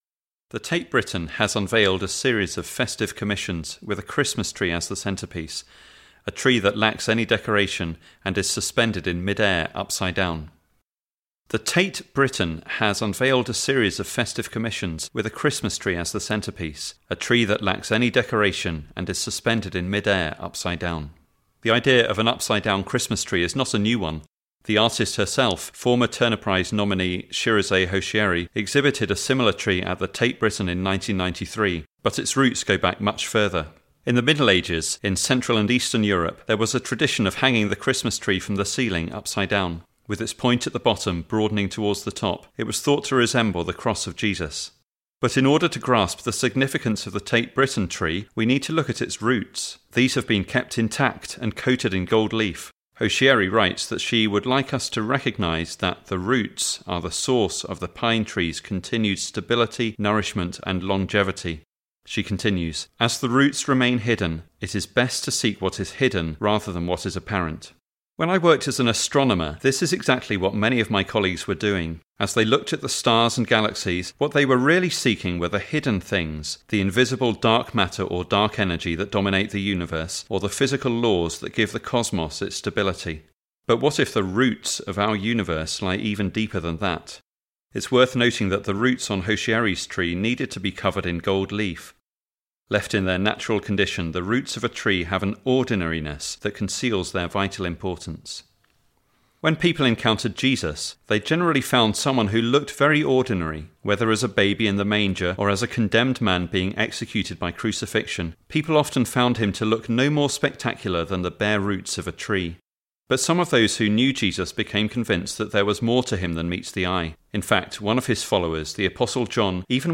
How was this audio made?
recorded 7th December at St John's College, Durham.